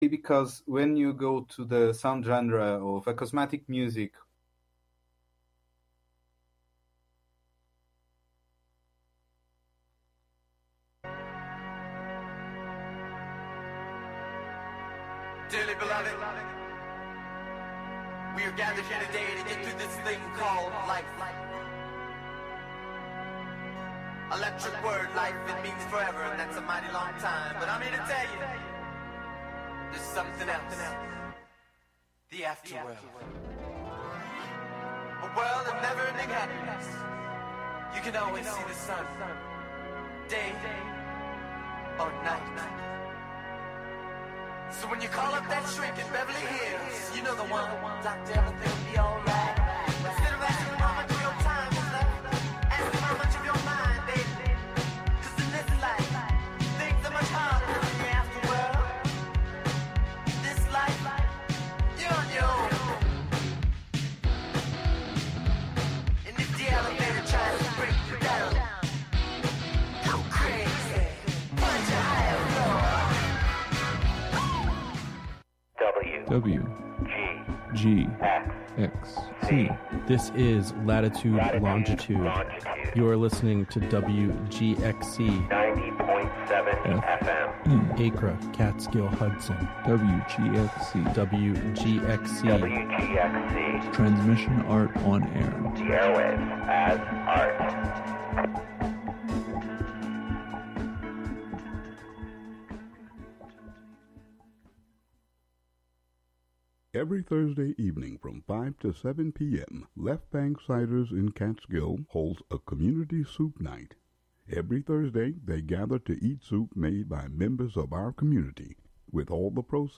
Counting down ten new sounds, stories, or songs, "American Top 40"-style. Usually the top ten is recent songs, but sometimes there are thematic countdowns, or local music-themed shows.